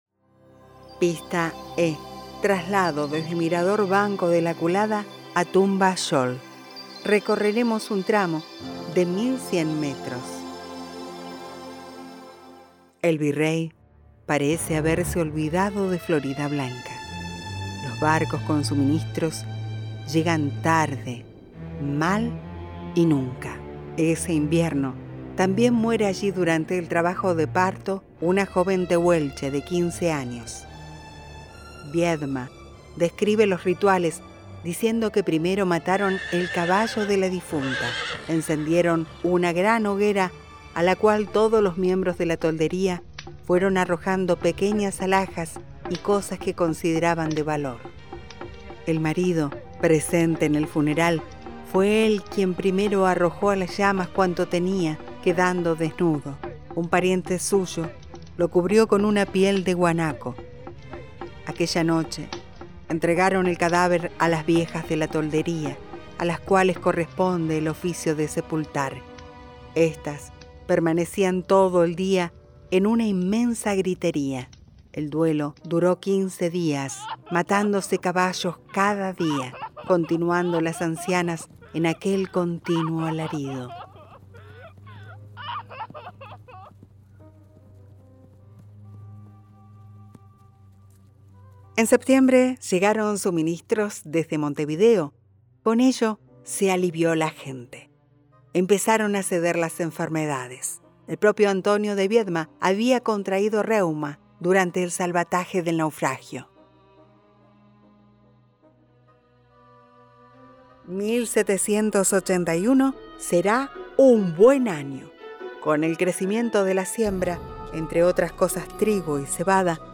Además, y para que esta propuesta sea más amena, incluimos también producciones musicales de nuestros artistas, que ilustran el paisaje y dan color al viaje.
Audioguía Vehicular Huelgas Patagónicas